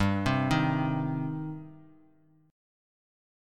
Gsus4#5 chord